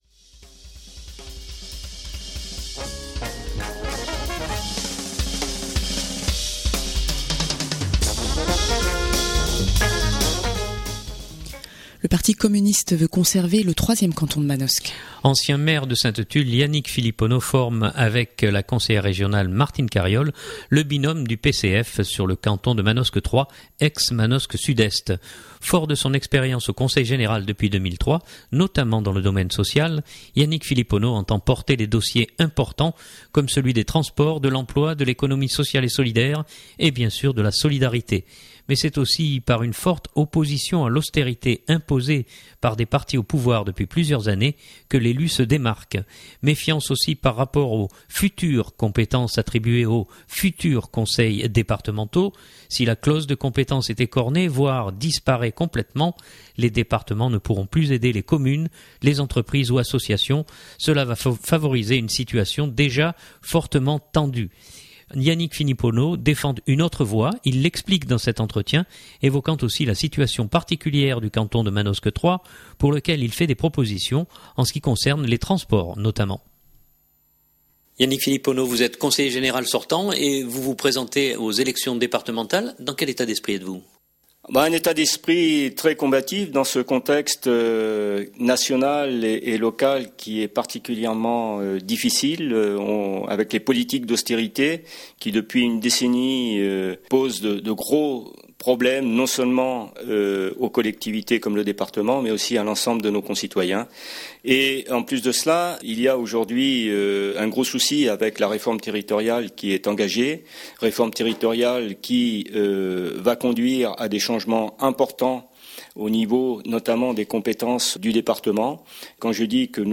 Il l’explique dans cet entretien évoquant aussi la situation particulière du canton de Manosque 3, pour lequel il fait des propositions en ce qui concerne les transports notamment.